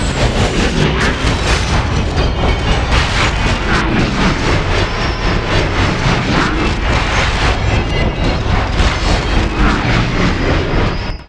chopperCrashingLoop.ogg